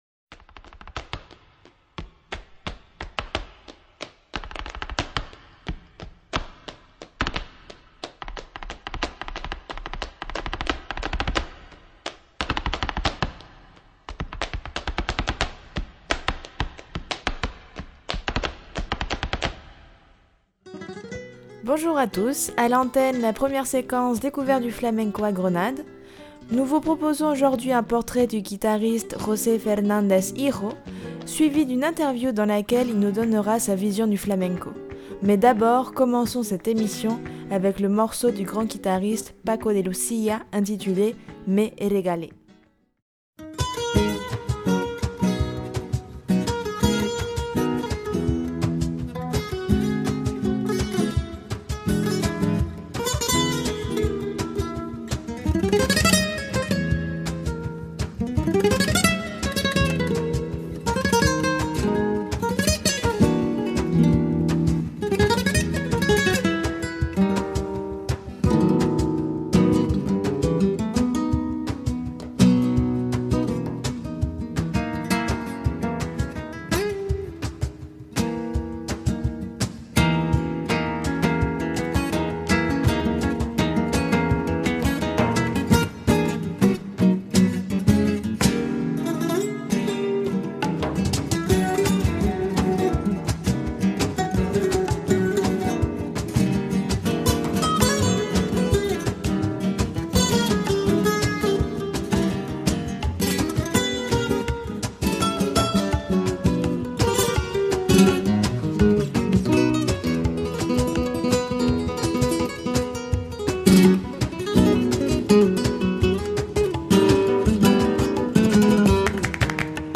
11 mars 2019 15:33 | Interview, reportage